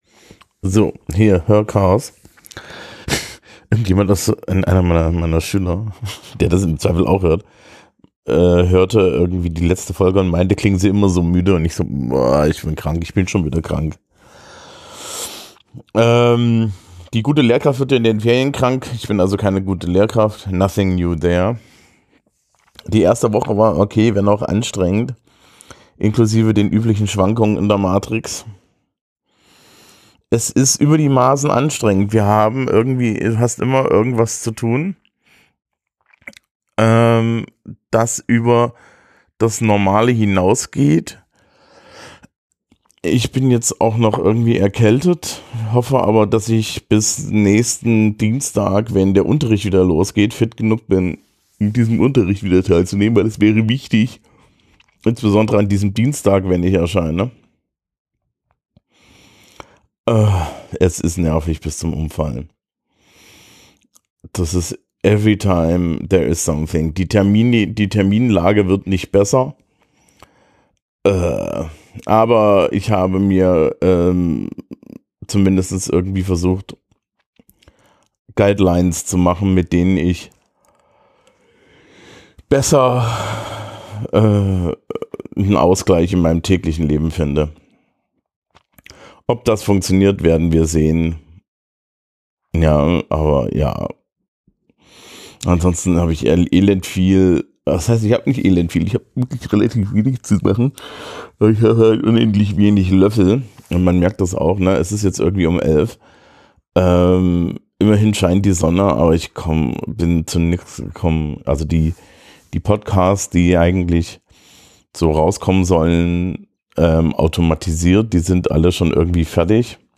Ich bin erkältet und müde, also äh ja... See you next week.